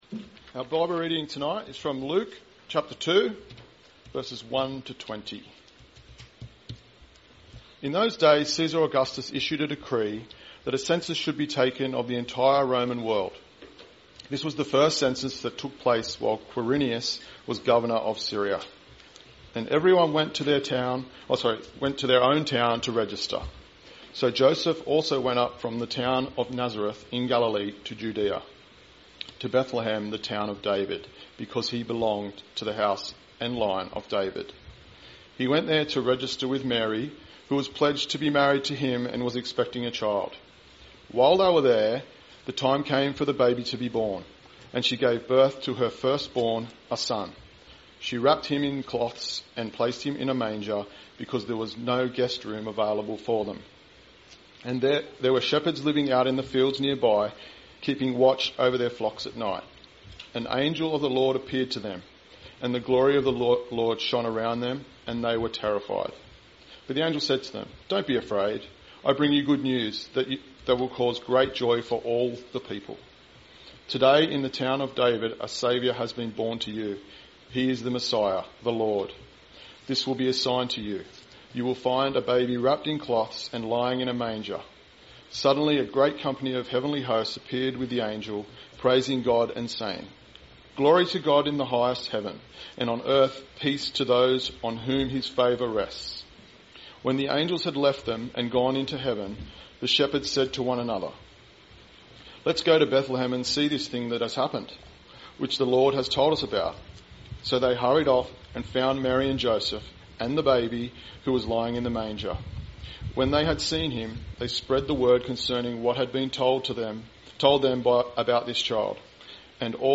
Christmas Eve Family Service – Luke 2:1-20; Luke 9:51